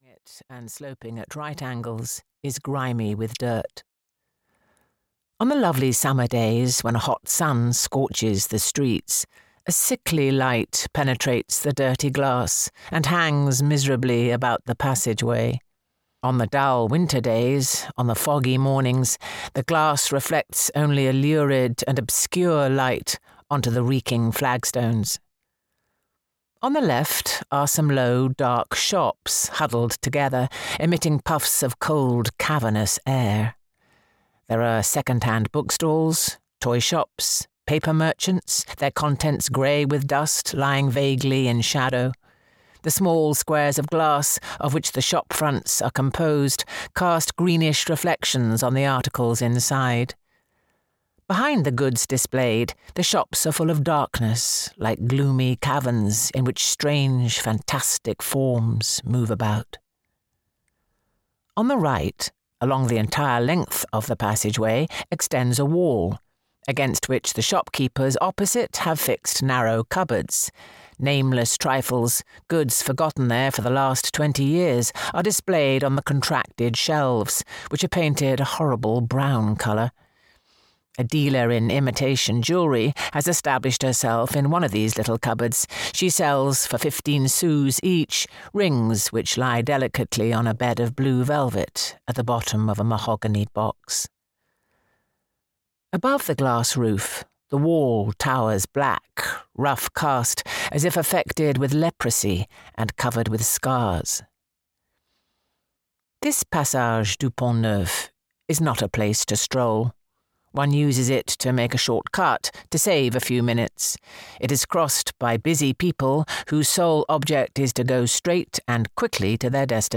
Thérèse Raquin (EN) audiokniha
Ukázka z knihy
• InterpretJuliet Stevenson